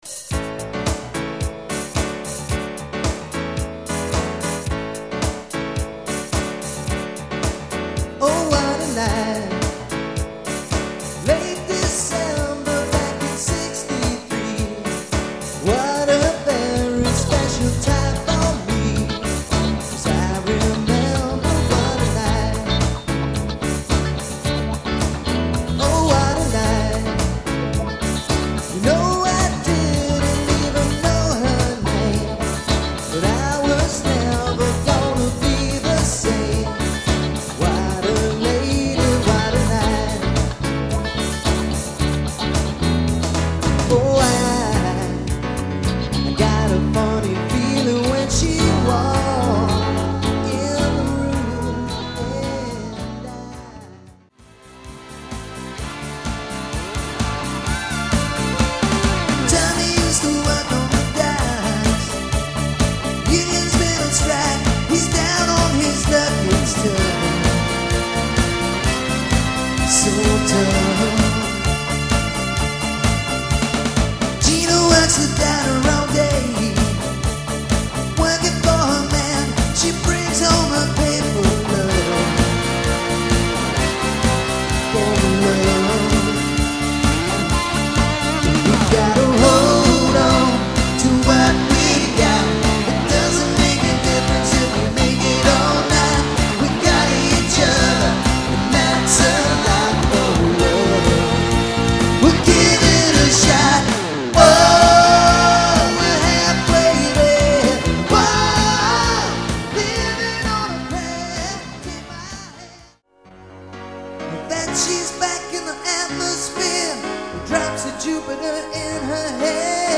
Guitars & Vocals
Drums & Vocals